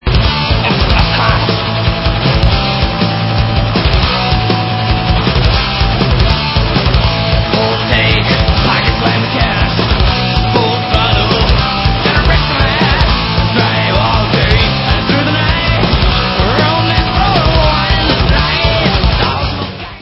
sledovat novinky v oddělení Heavy Metal